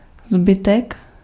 Ääntäminen
IPA: [ʁɛst]